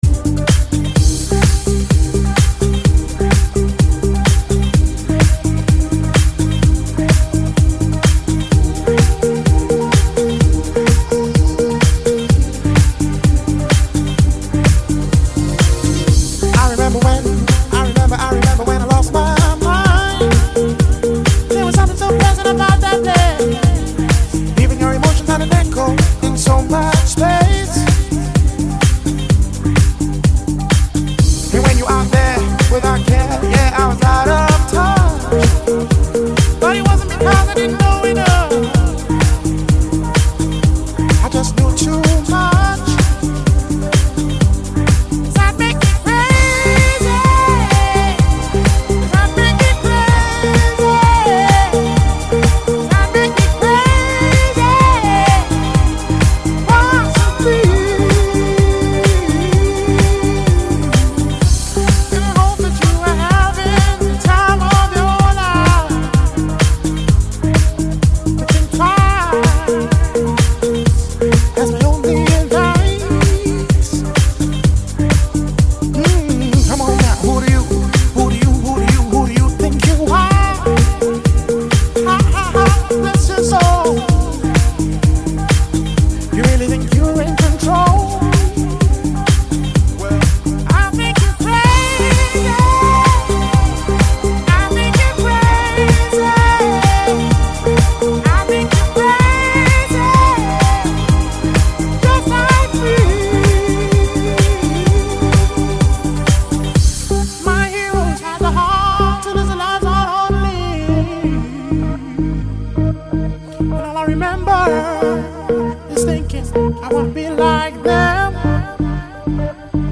现场包房打碟